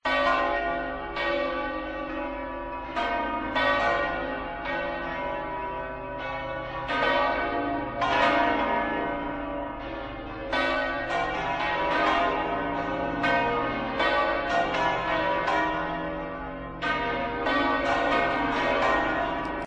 El sonido de las campanas de La Almudena el 11-S